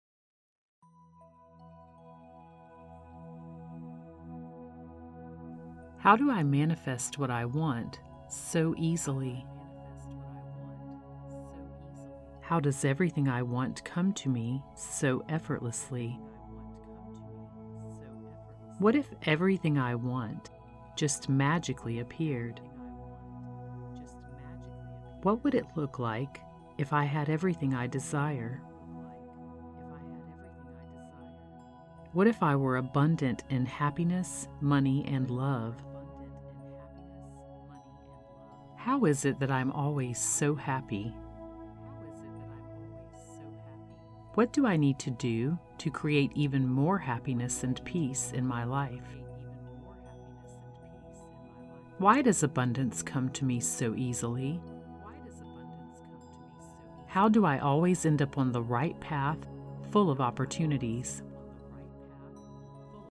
Here, I have created a meditation with over 50 questions that will prompt your mind to search for answers to prove your success in every area of your life and assist you with manifesting your desires in the quickest and easiest manner. You will also hear a subtle, subliminal repeat after each affirmation to further impress the subconscious mind.
This is the 1-Hour Version with almost 1,000 affirmation impressions that can be played while you are sleeping, or it can be played in the background as you move through your day.